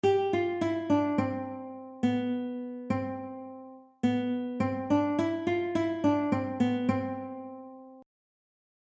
Joyful! Joyful! - Guitar Sight Reading Exercise | SightReadingMastery